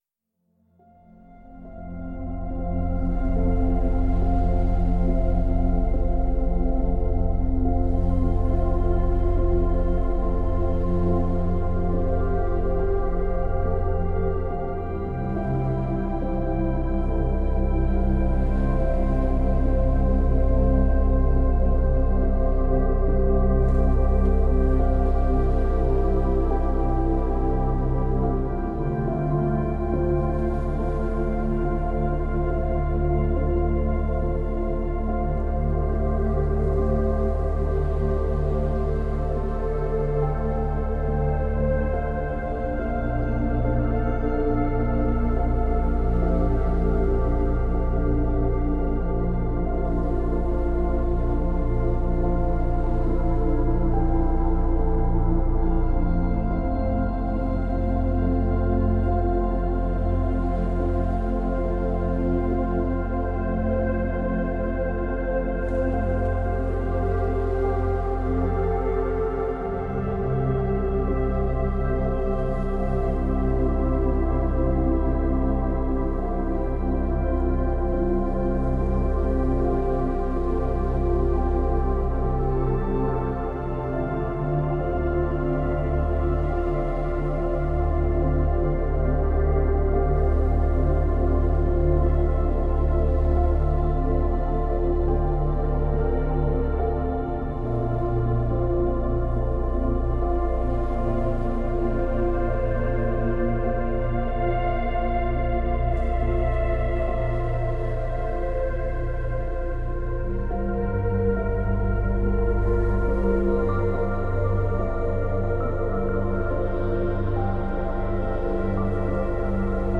La fréquence 432 Hz efface la douleur corporelle
frequence-432-Hz-pour-effacer-la-douleur-et-trouble-emotionnel-du-passe.mp3